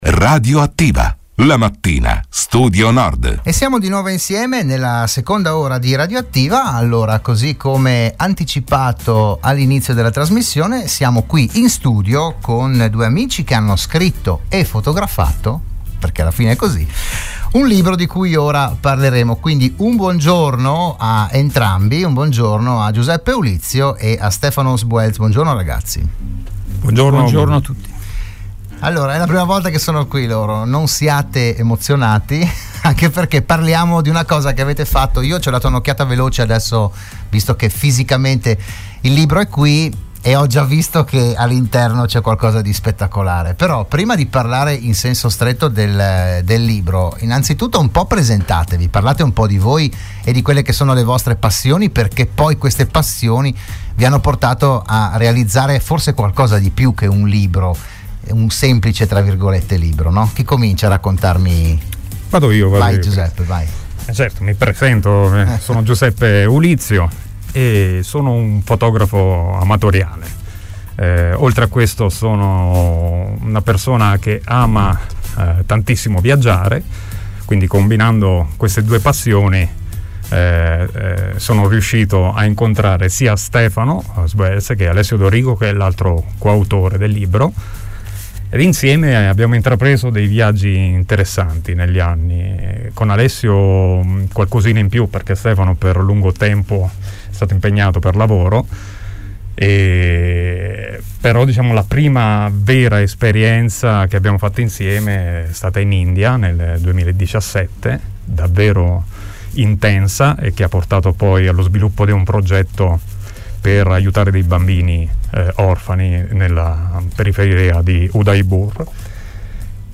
Del libro si è parlato oggi “ RadioAttiva “, la trasmissione di Radio Studio Nord